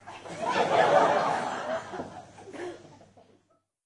剧院 " 笑3
描述：LaughLaugh在中型剧院用MD和索尼麦克风录制，在人的上方
Tag: 听众 礼堂 人群 捷克 布拉格 戏剧